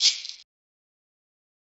Perc [ yuh ].wav